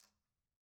Quinto-Tap1_v1_rr2_Sum.wav